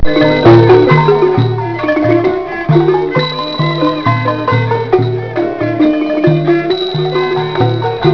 Laotian dancing girls
Parasol Blanc Hotel, Luang Prabang, Laos